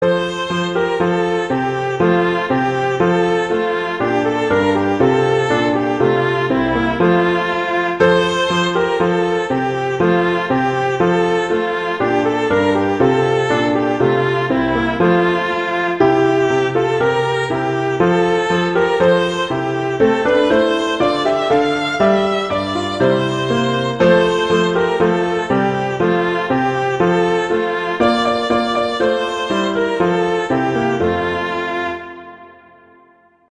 viola and piano